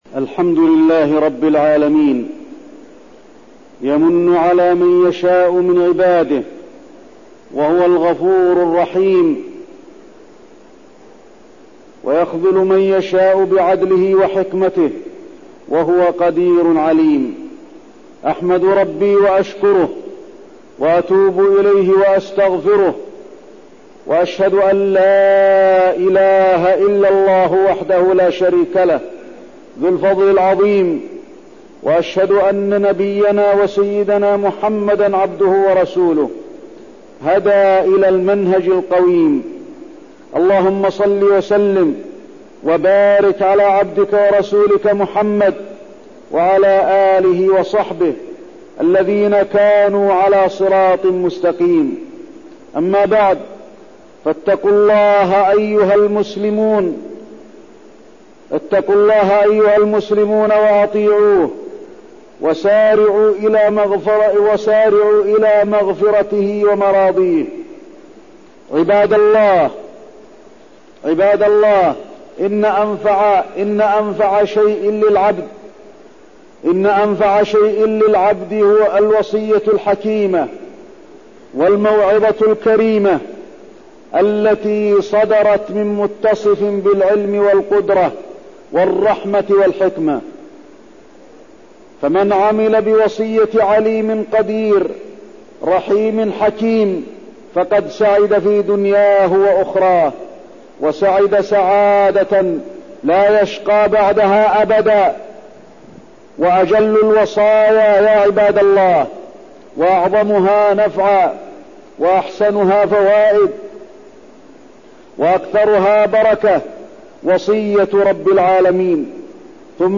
تاريخ النشر ٦ ربيع الثاني ١٤١٣ هـ المكان: المسجد النبوي الشيخ: فضيلة الشيخ د. علي بن عبدالرحمن الحذيفي فضيلة الشيخ د. علي بن عبدالرحمن الحذيفي وصية لقمان لإبنه